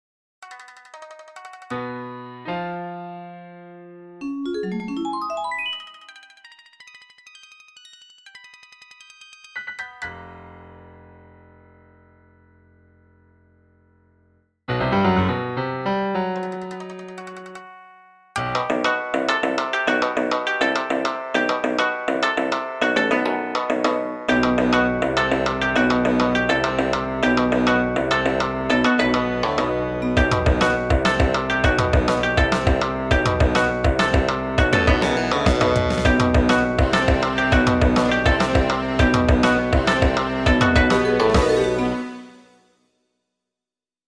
The first part incorporates some algorithmic elements, but heavily modified. The second half is more like my usual style, but in an odd meter (10/4, I think).
It's pretty short, but "something is better than nothing."
ALGORITHMIC MUSIC; PROGRESSIVE ROCK MUSIC